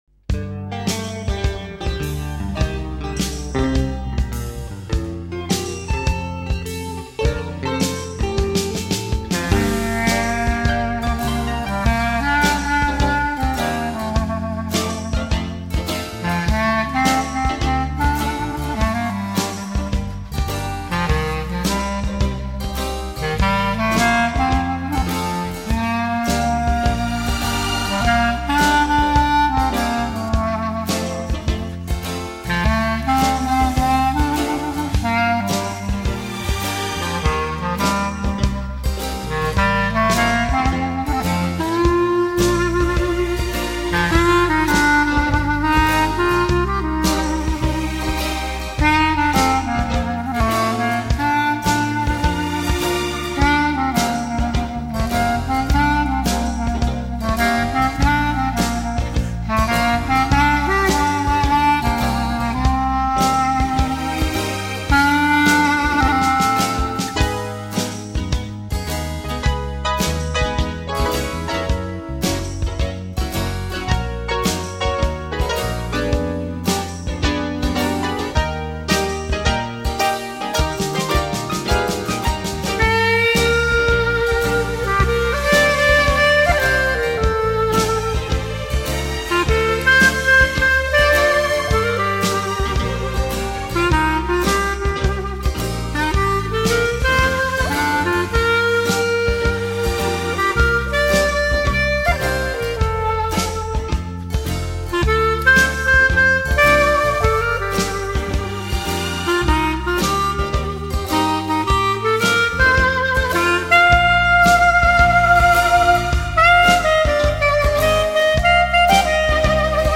爵士单簧管专辑